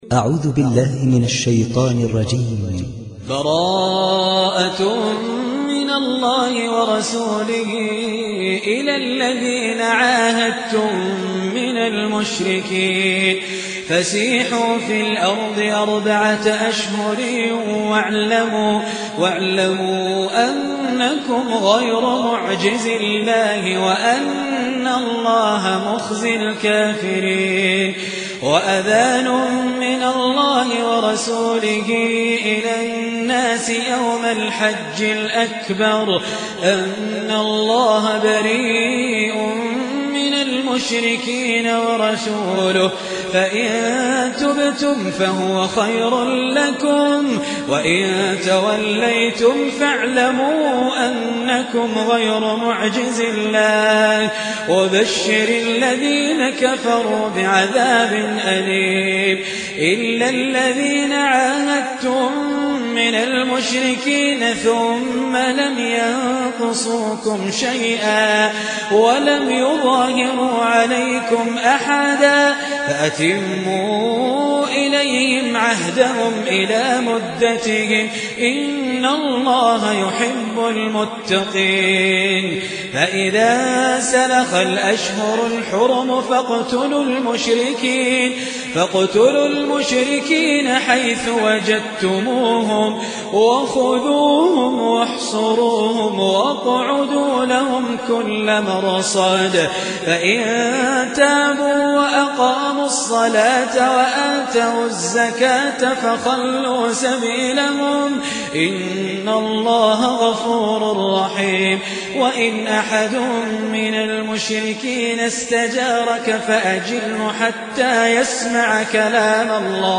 تحميل سورة التوبة mp3 بصوت خالد الجليل برواية حفص عن عاصم, تحميل استماع القرآن الكريم على الجوال mp3 كاملا بروابط مباشرة وسريعة